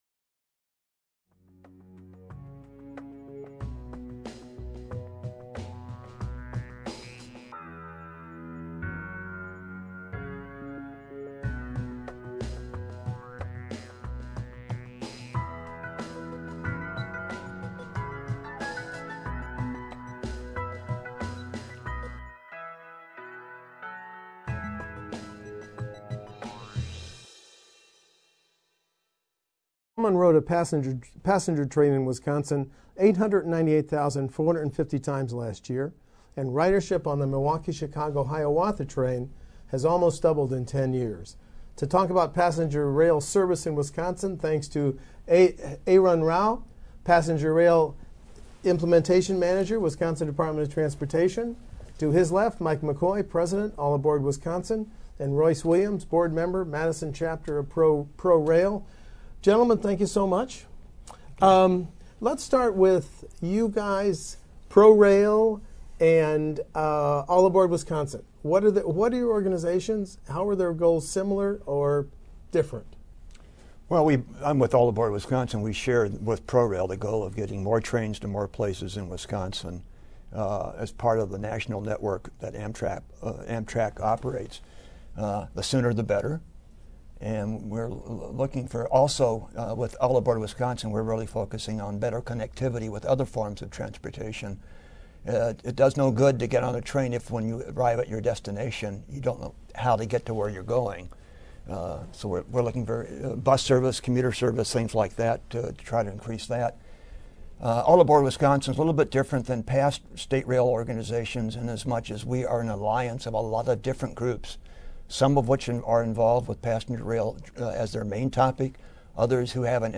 The interview will take place the WisconsinEye studio on January 23, 2014.